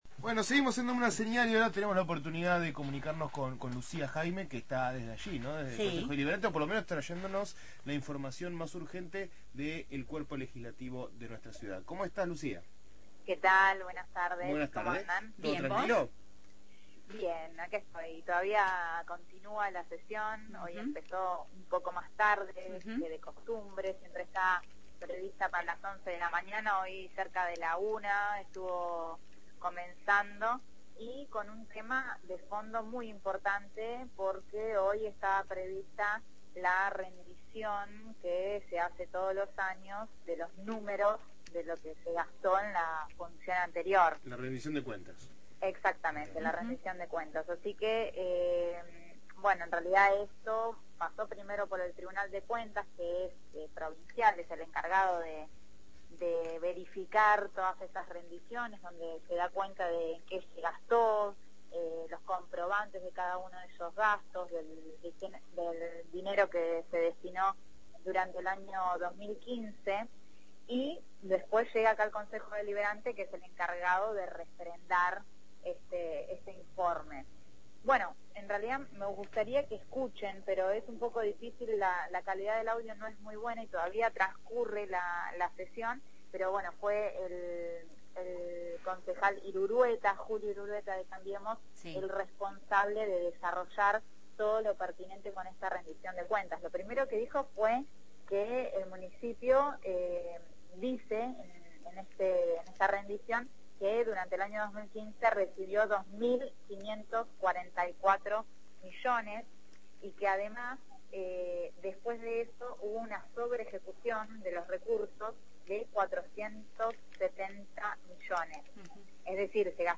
Móvil/ Concejo Deliberante de La Plata, rendición de cuentas – Radio Universidad